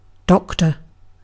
Ääntäminen
UK : IPA : /ˈdɒk.tə/ US : IPA : /ˈdɑk.tɚ/